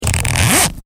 Zipper 01 .LR